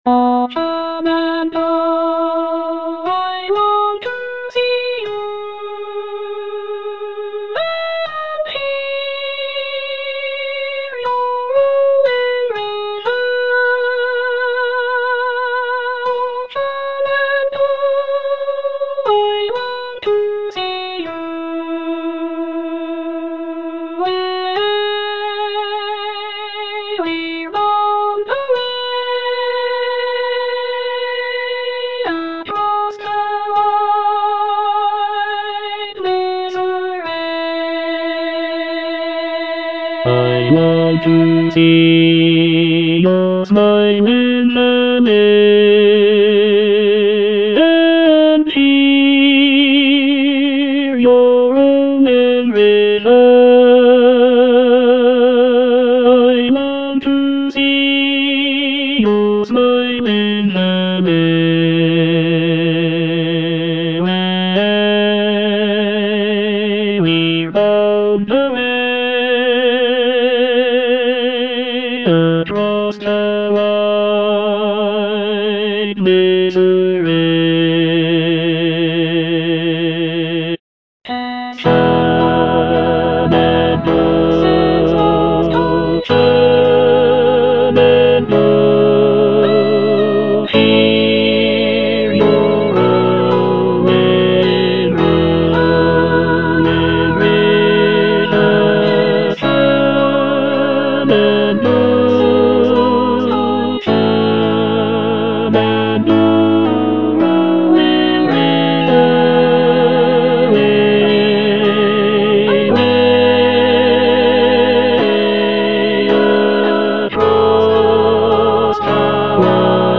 Tenor II (Emphasised voice and other voices)